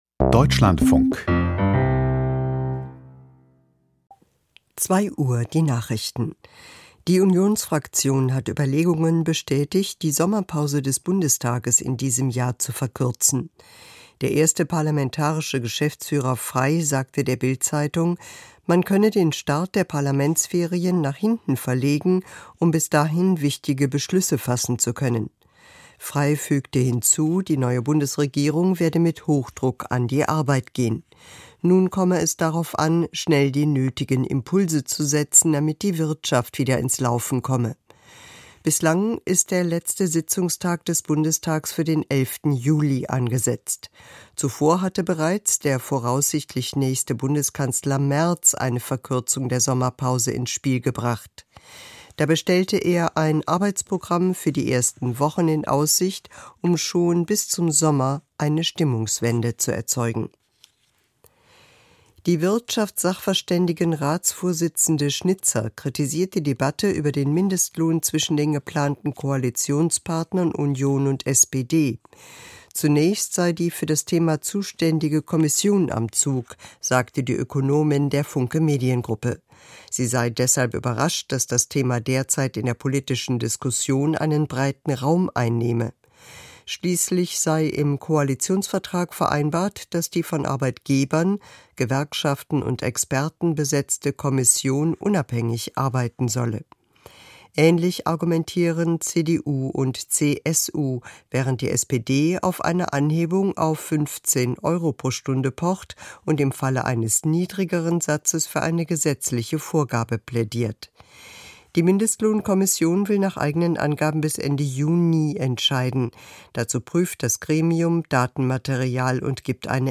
Die Deutschlandfunk-Nachrichten vom 25.04.2025, 02:00 Uhr